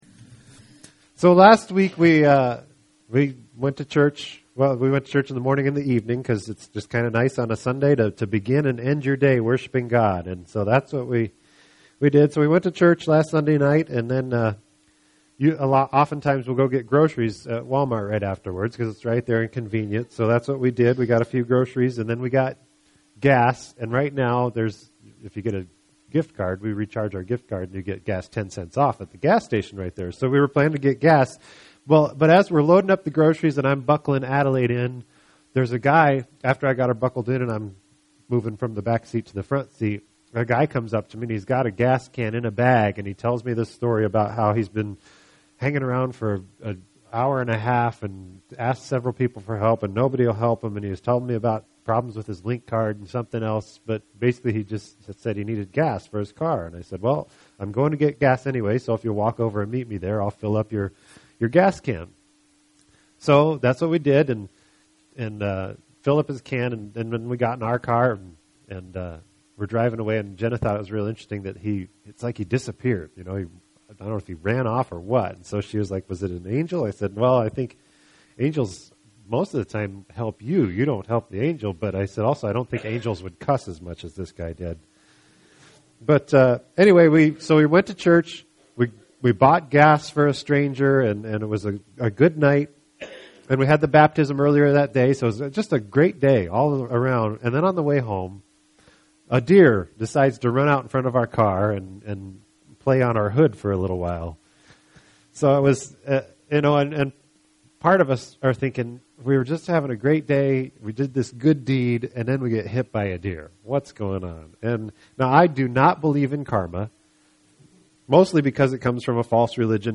Podcast: Play in new window | Download (Duration: 47:45 — 21.9MB) This entry was posted on Saturday, October 27th, 2012 at 1:23 am and is filed under Sermons .